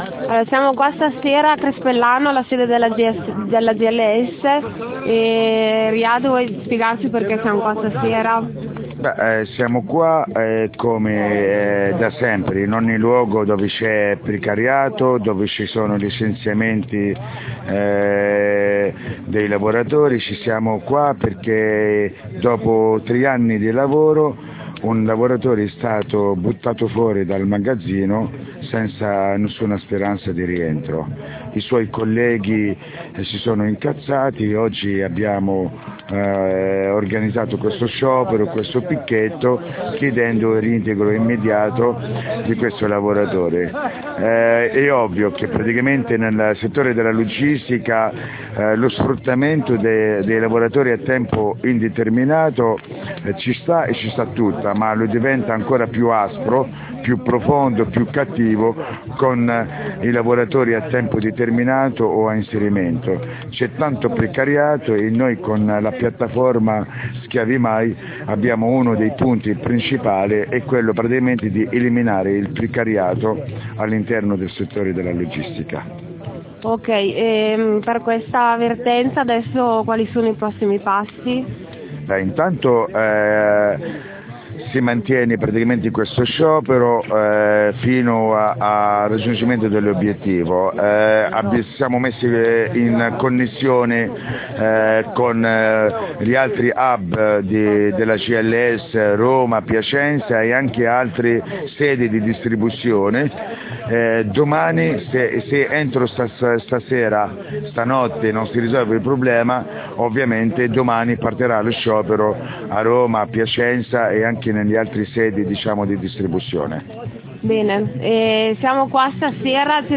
Intervista
durante i blocchi di USB a Crespellano